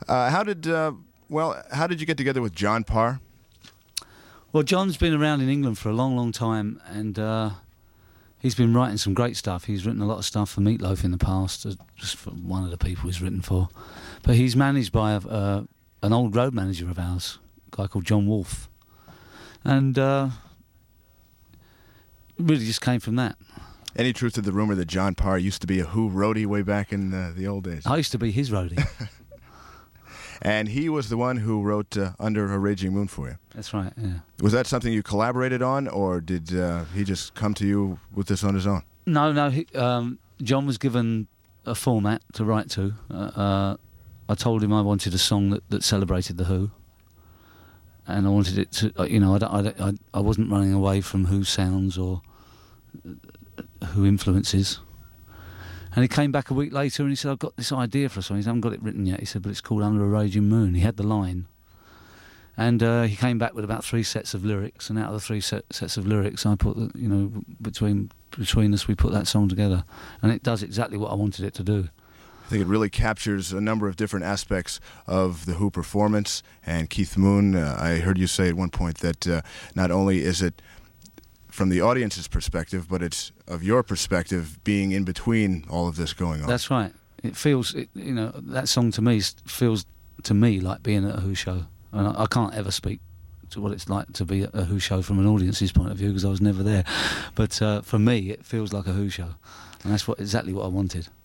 roger-daltrey-interview.mp3